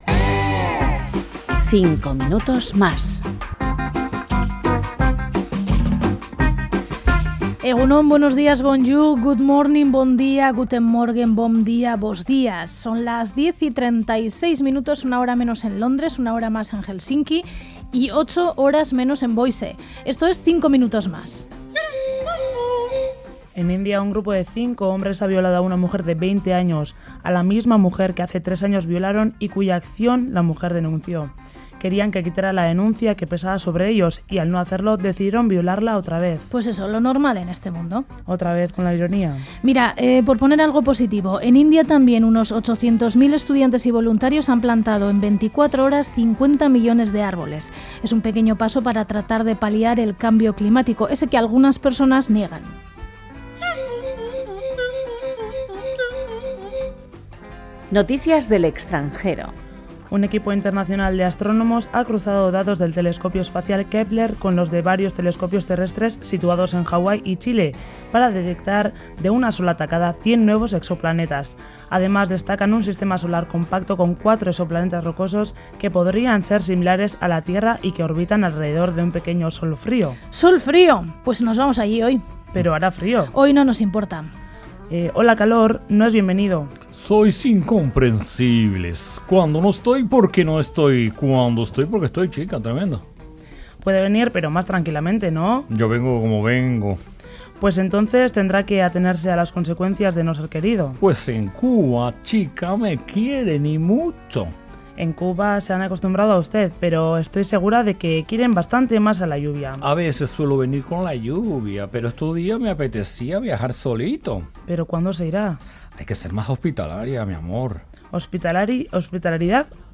5 Minutos Mas| Informativo| RADIO EUSKADI